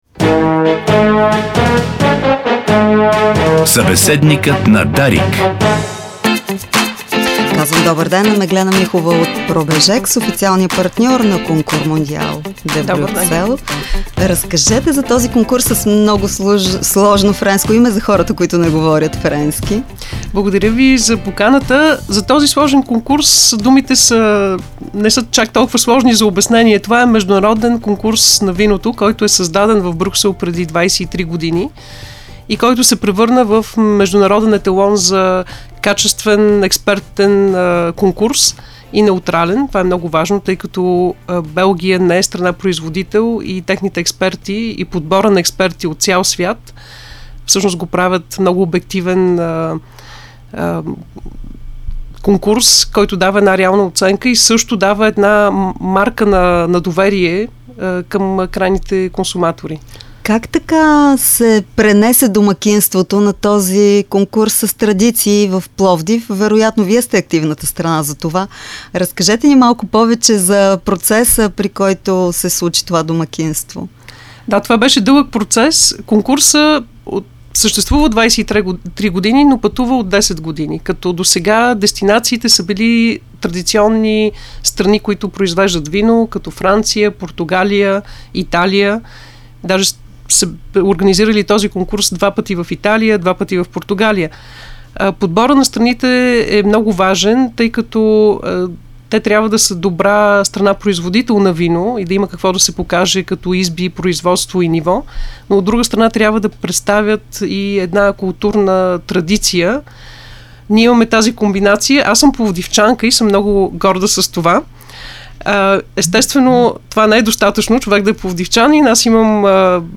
Това каза в ефира на Дарик